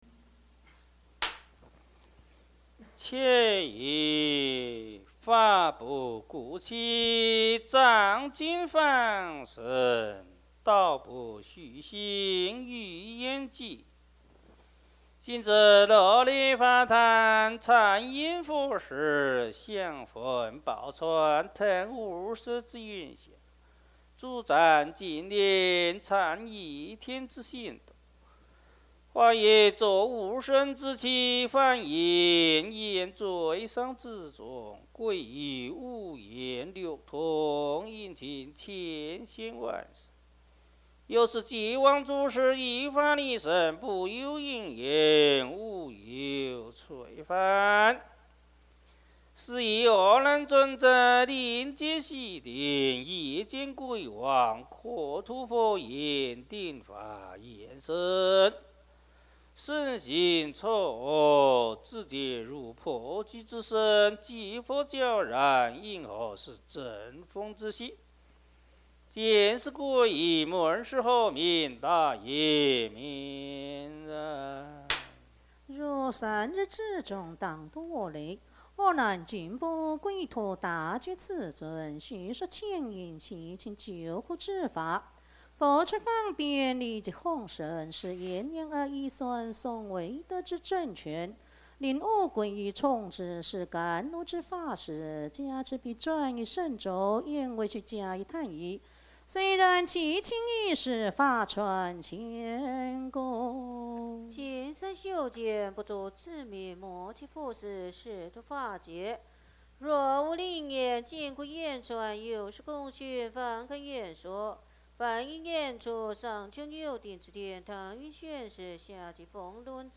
佛教音樂  Mp3音樂免費下載 Mp3 Free Download